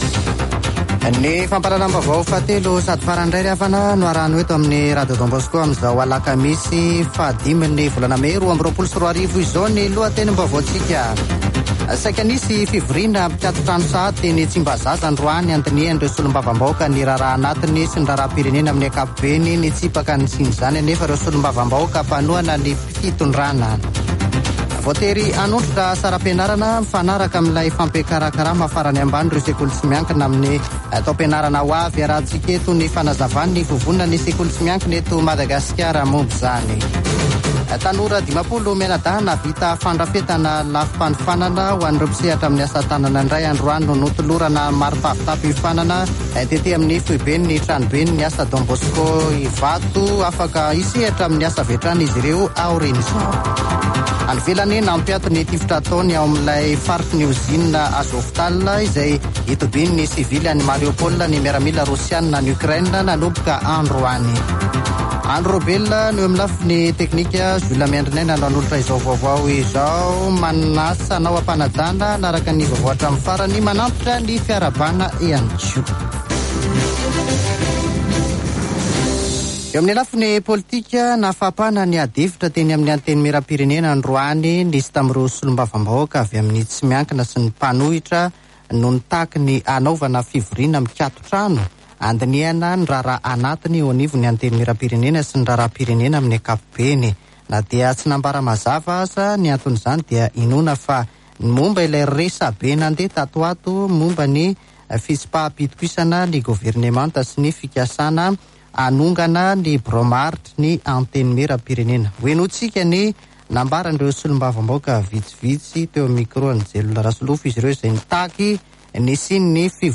[Vaovao hariva] Alakamisy 5 mey 2022